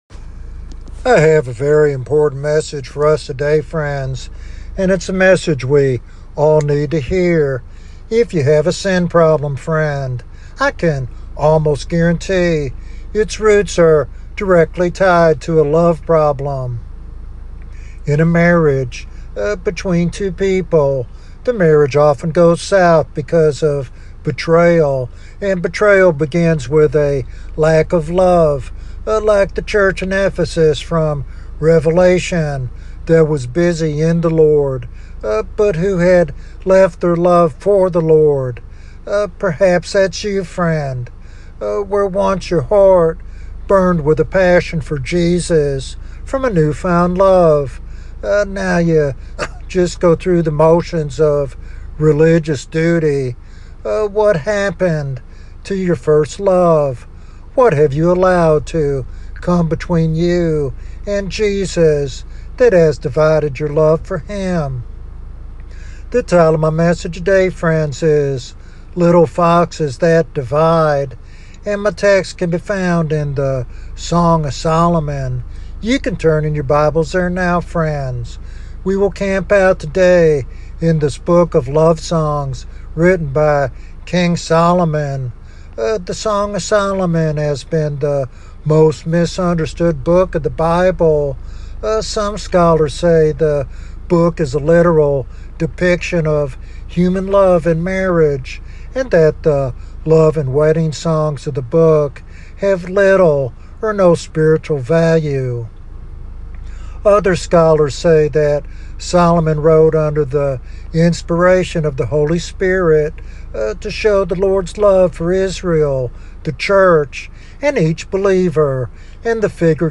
This topical sermon challenges listeners to identify these barriers, repent, and rekindle their passion for God’s unfailing love.